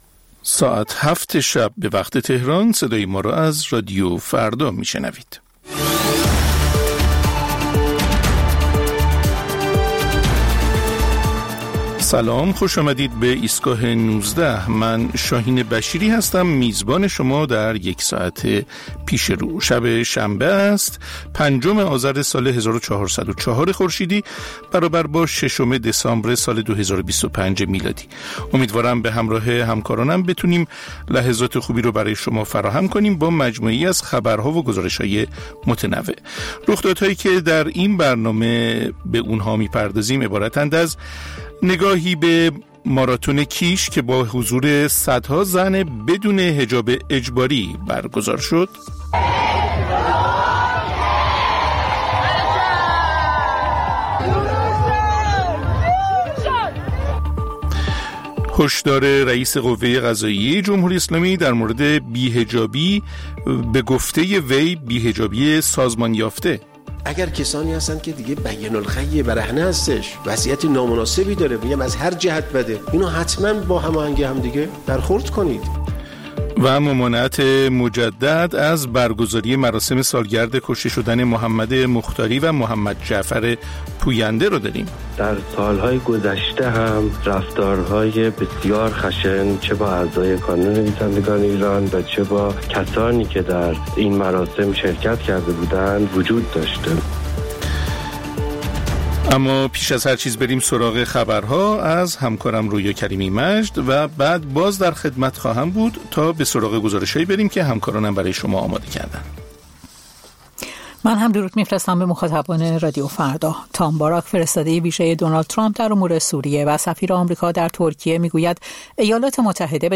مجموعه‌ای از اخبار، گزارش‌ها و گفت‌وگوها در ایستگاه ۱۹ رادیو فردا